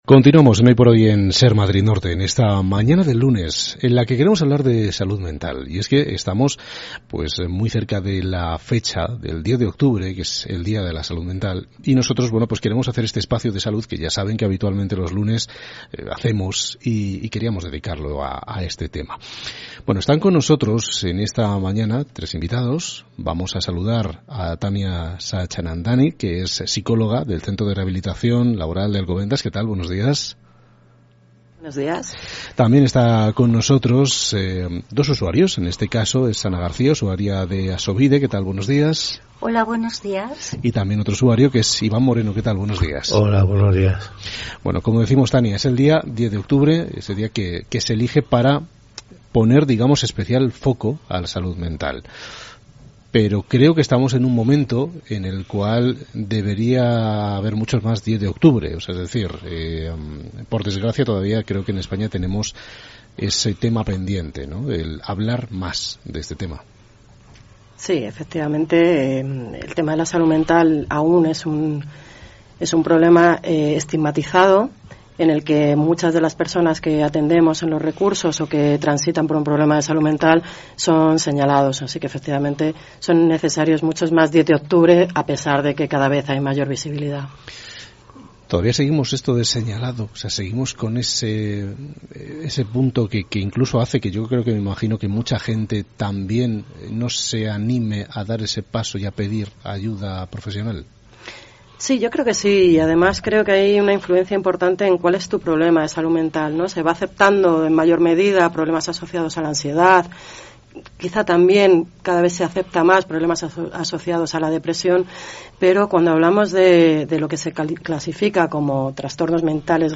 hoy-por-hoy-entrevista-dia-mundia-salud-mental.mp3